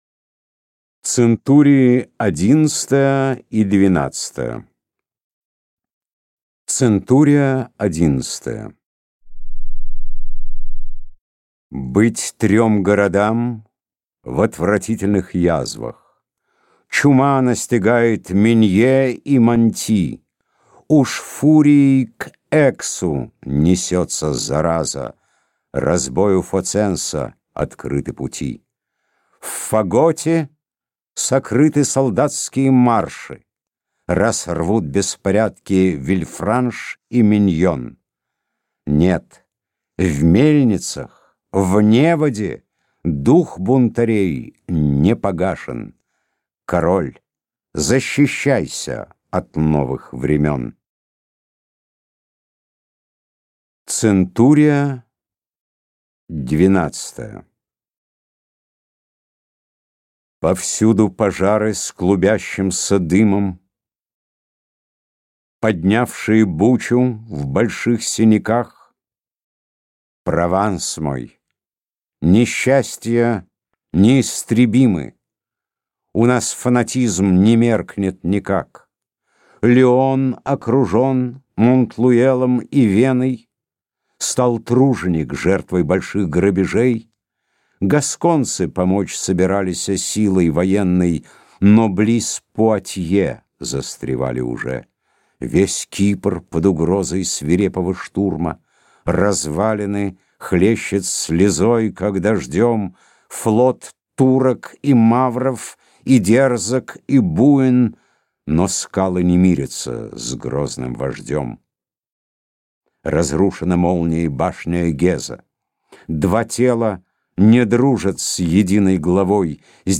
Аудиокнига Центурии | Библиотека аудиокниг